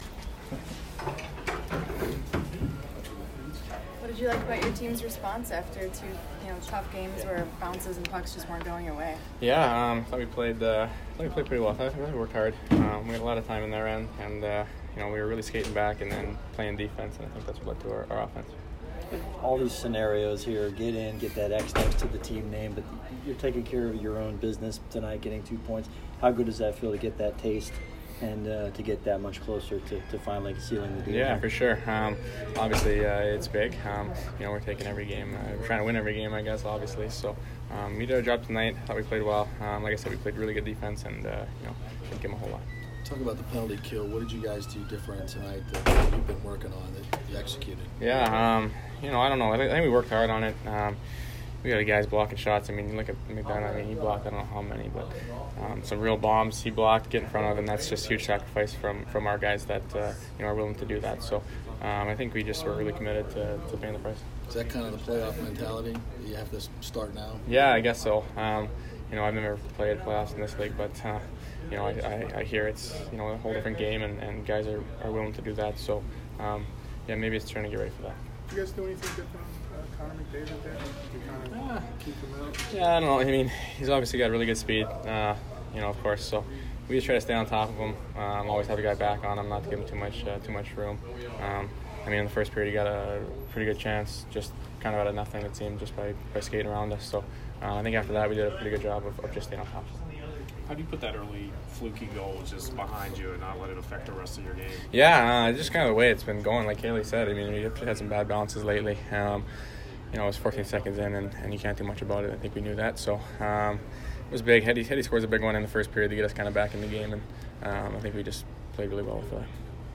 Brayden Point post-game 3/18